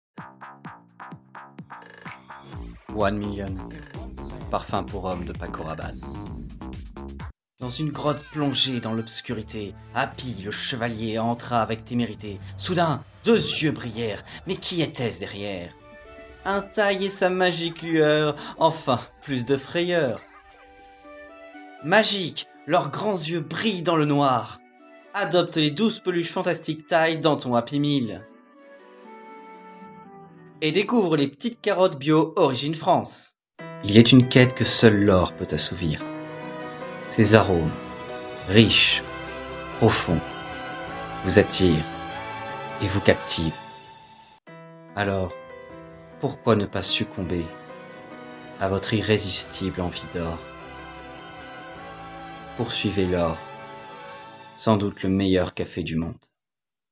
Bande-démo voix-off